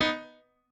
piano8_31.ogg